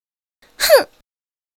女声撒娇哼一声音效免费音频素材下载